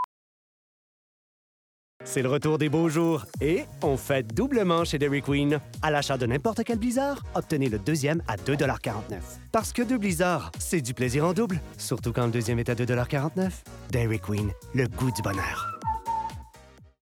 Dairy Queen - Announcer